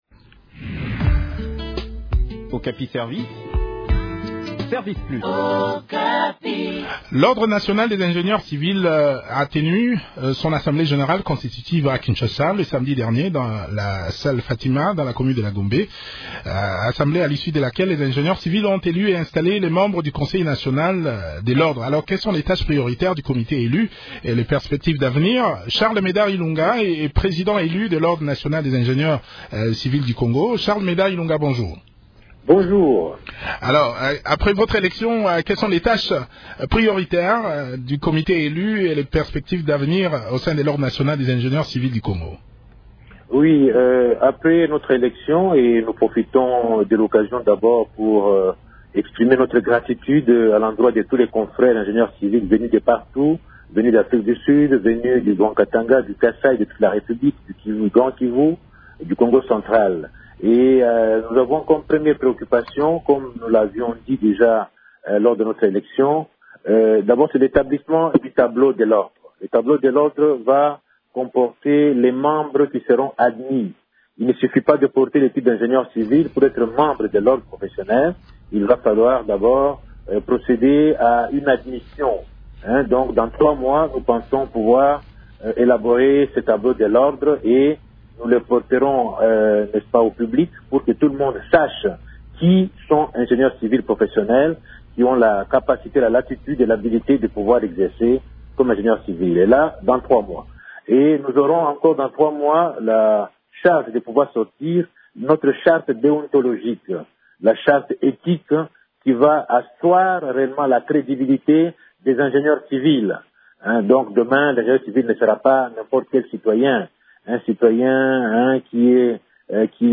Le point sur le déroulement de cette assemblée générale dans cet entretien